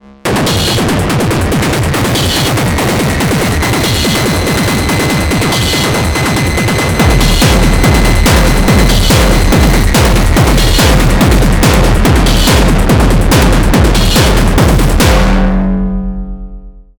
Here are two Syntakt jams using digital and analog tracks (I picked my most recent most noisy things):